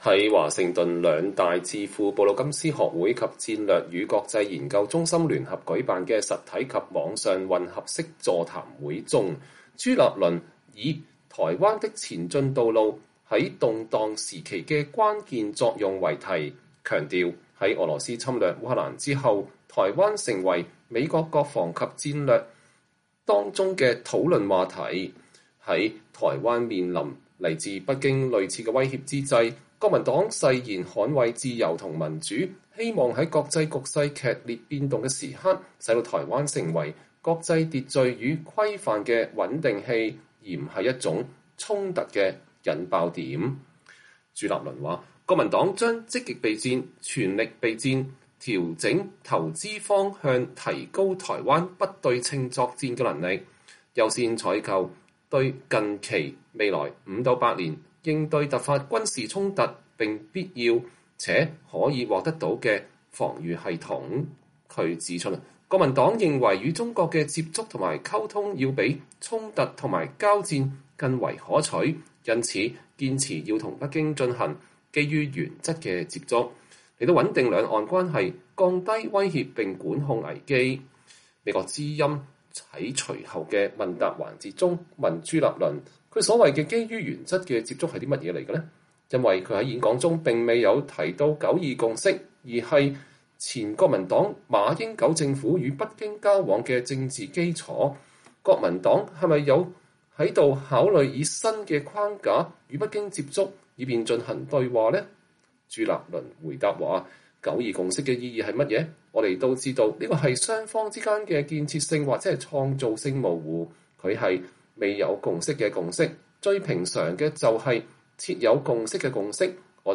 國民黨主席朱立倫在華盛頓演說：九二共識是台海兩岸“沒有共識的共識”
在華盛頓兩大智庫布魯金斯學會及戰略與國際研究中心聯合舉辦的實體及線上混合式座談中，朱立倫以“台灣的前進道路--在動蕩時期的關鍵作用”為題，強調在俄羅斯侵略烏克蘭以後，台灣成為美國國防及戰略的討論話題，在台灣面臨來自北京類似威脅之際，國民黨誓言捍衛自由和民主，希望在國際局勢劇烈變動的時刻使台灣成為國際秩序與規範的“穩定器”，而不是一個衝突的“引爆點”。